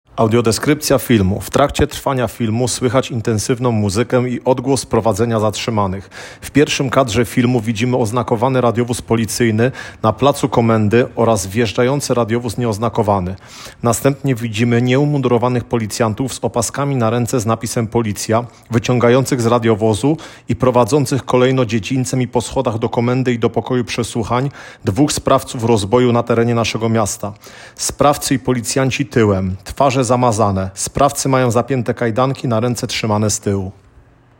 Nagranie audio Audiodesrypcja filmu.